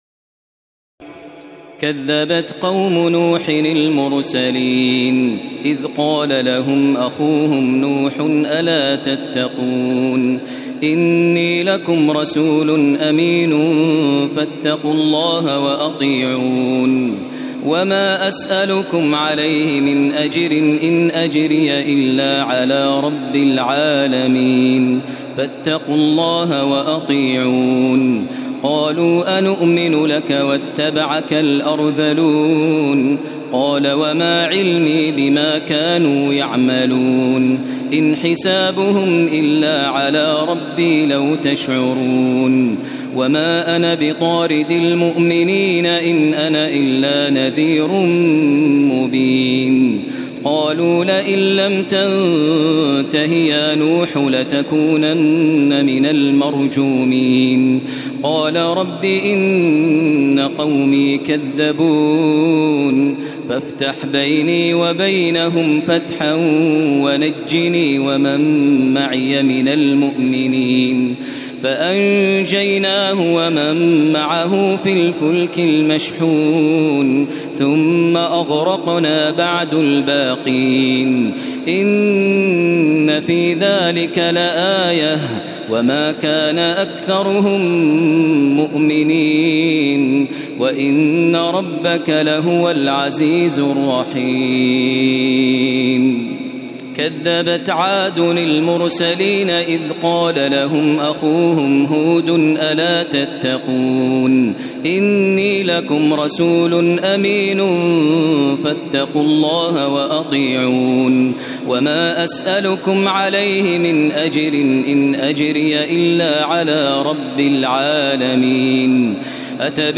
تراويح الليلة الثامنة عشر رمضان 1432هـ من سورتي الشعراء (105-227) والنمل (1-53) Taraweeh 18 st night Ramadan 1432H from Surah Ash-Shu'araa and An-Naml > تراويح الحرم المكي عام 1432 🕋 > التراويح - تلاوات الحرمين